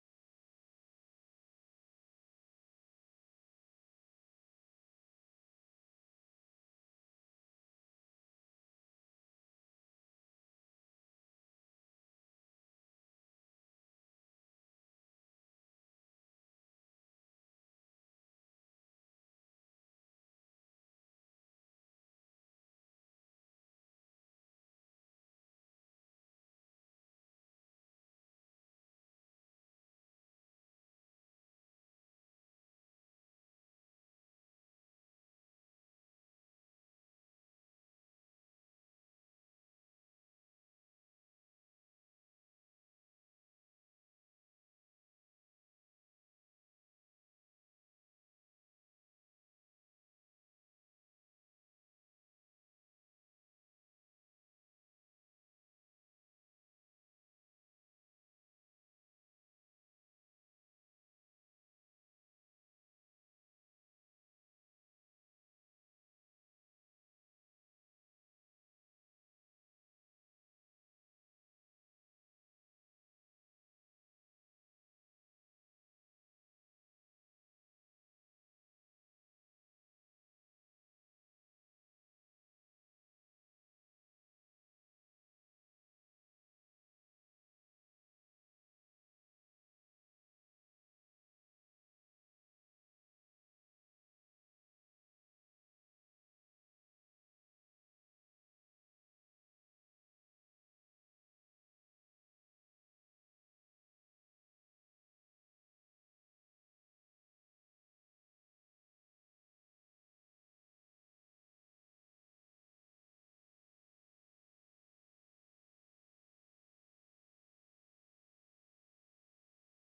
Functie: Presentator
Opgewekte muziek speelt.